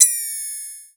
Index of /musicradar/essential-drumkit-samples/Vermona DRM1 Kit
Vermona Open Hat 01.wav